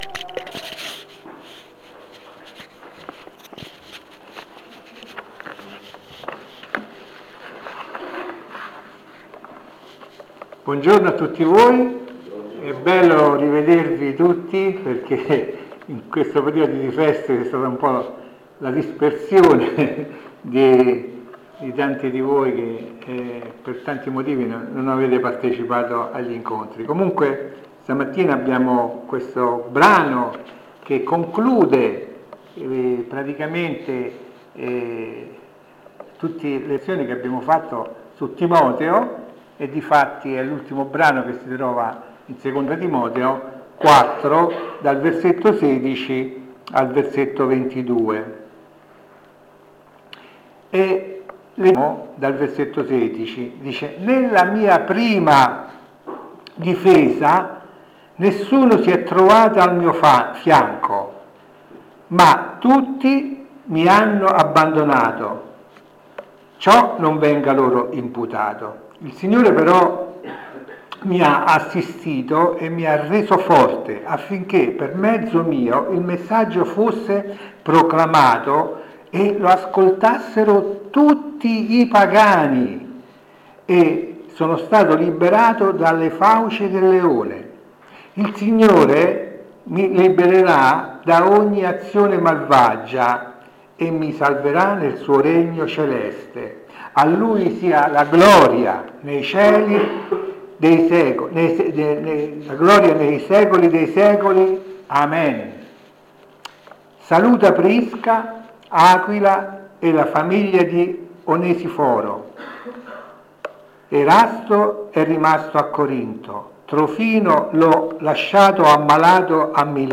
Insegnamenti biblici sul passo di 2 Timoteo 4:16-22.